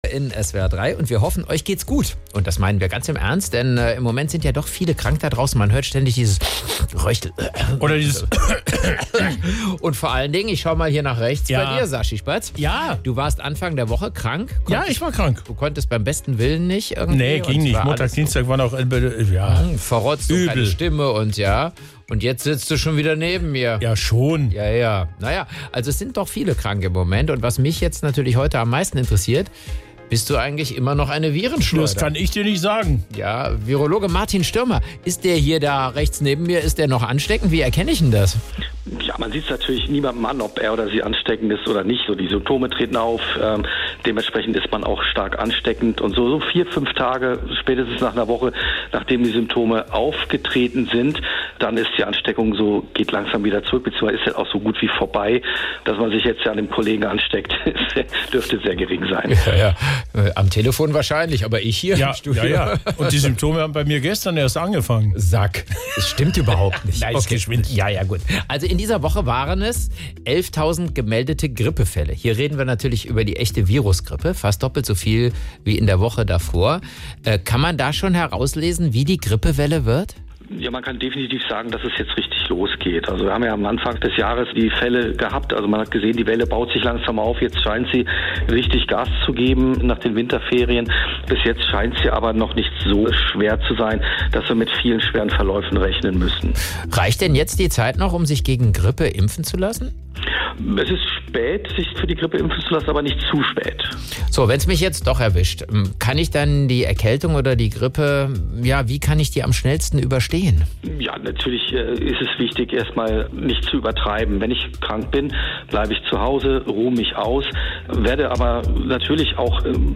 SWR3 Moderatoren